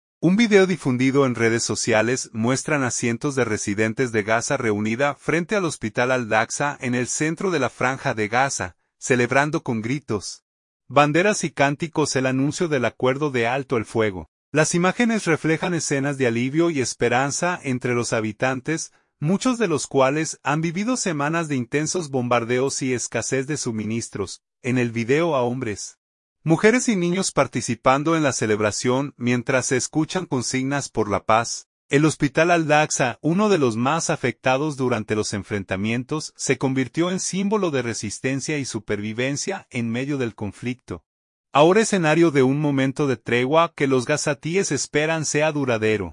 Un video difundido en redes sociales muestran a cientos de residentes de Gaza reunida frente al Hospital Al-Aqsa, en el centro de la Franja de Gaza, celebrando con gritos, banderas y cánticos el anuncio del acuerdo de alto el fuego.
En el video a hombres, mujeres y niños participando en la celebración, mientras se escuchan consignas por la paz.